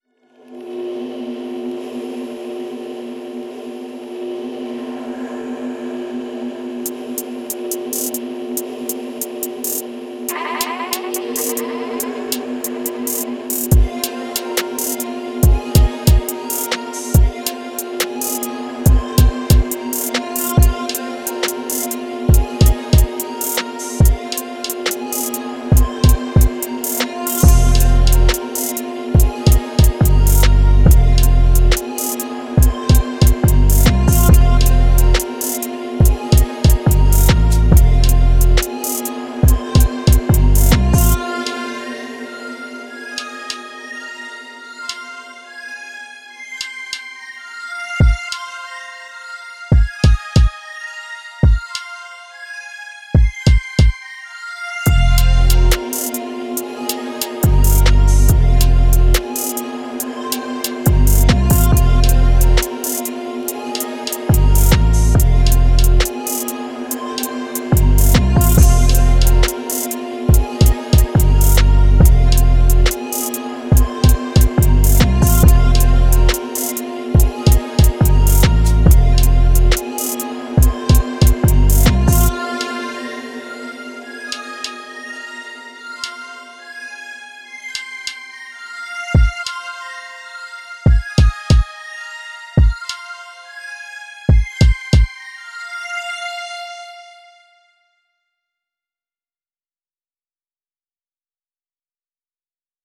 Time – (1:42)　bpm.140